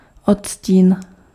Ääntäminen
Synonyymit subtilité différence Ääntäminen France: IPA: [yn nɥɑ̃s] Tuntematon aksentti: IPA: /ny.ɑ̃s/ IPA: /nɥɑ̃s/ Haettu sana löytyi näillä lähdekielillä: ranska Käännös Ääninäyte Substantiivit 1. odstín {m} Suku: f .